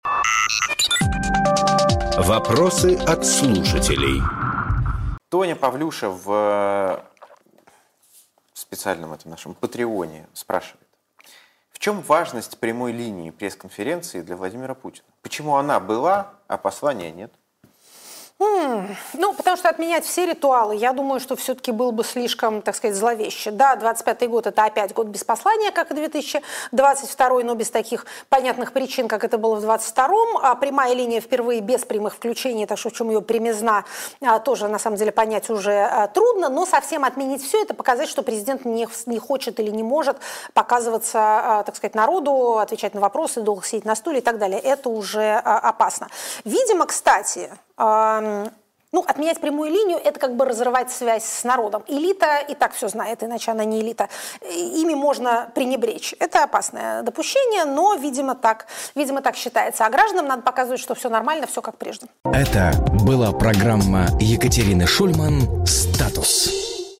Екатерина Шульманполитолог
Фрагмент эфира от 23.12.25